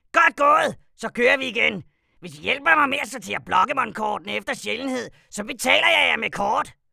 Factory puzzle feedback and VO
hrcement_puzzlecomplete.wav